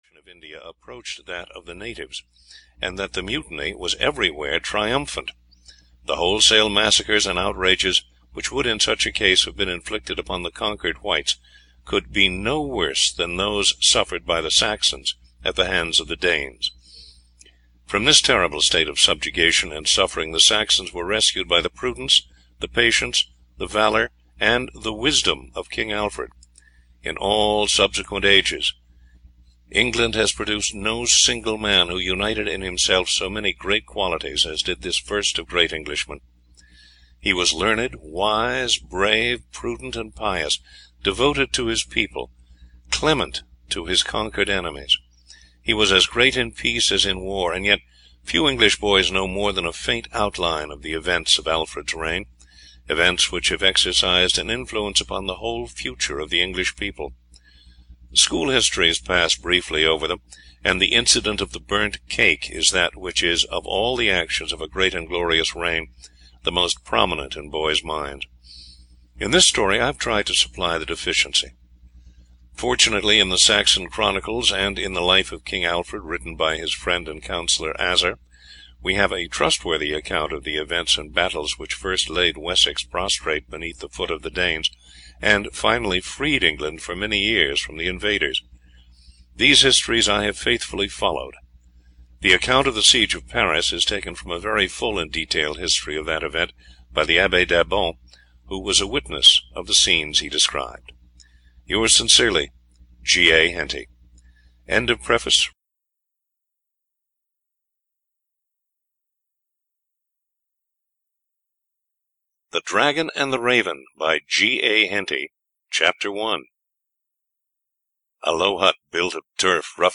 The Dragon and the Raven (EN) audiokniha
Ukázka z knihy